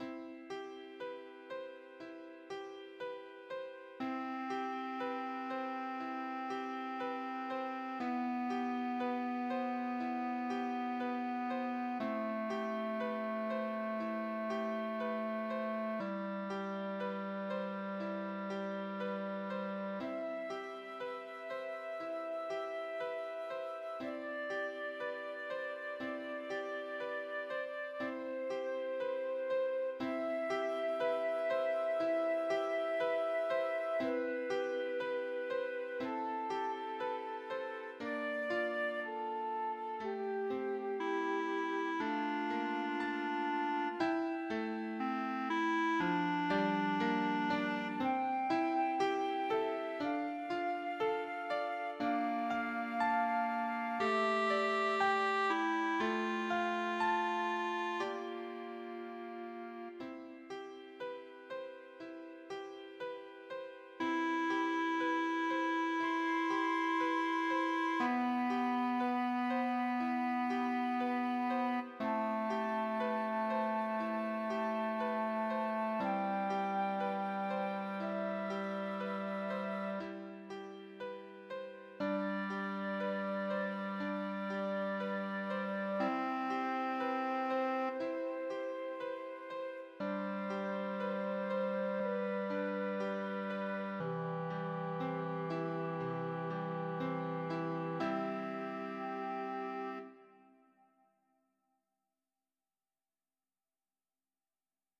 TRIO  Flute, clarinet, easy piano 1’ 40”